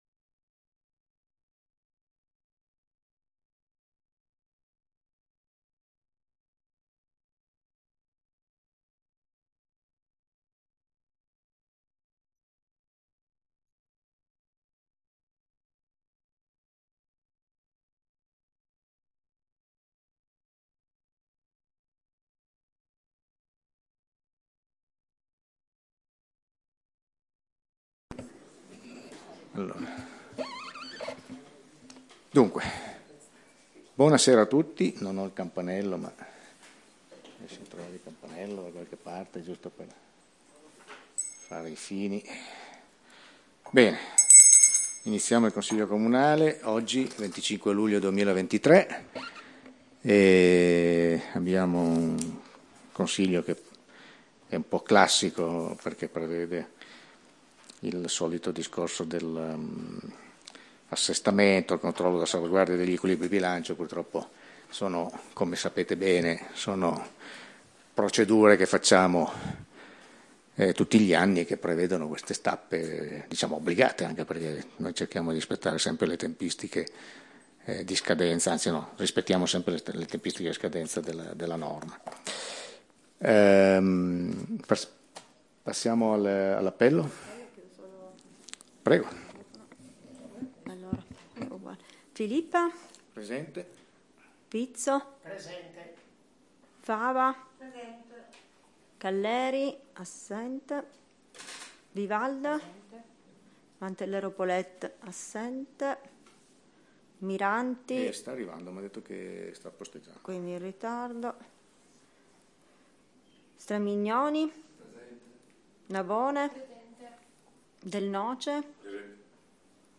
Registrazione Consiglio comunale - Commune de Pecetto Torinese